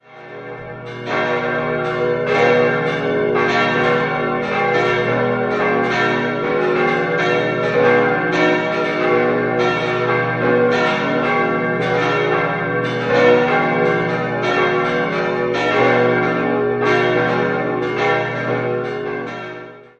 Die heutige Kirche wurde 1780 im Übergangsstil vom Barock zum Klassizismus fertiggestellt. 5-stimmiges Geläut: h°-dis'-eis'-gis'-h' Die Glocken wurden 1948 vom Bochumer Verein für Gussstahlfabrikation gegossen.